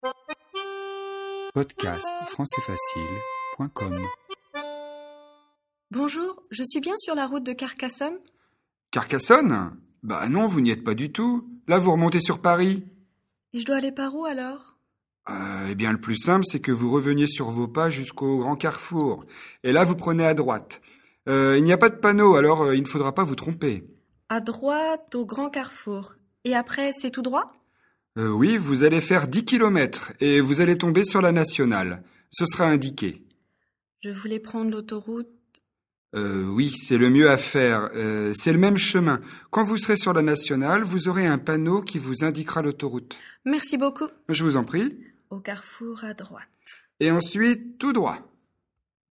🔷 DIALOGUE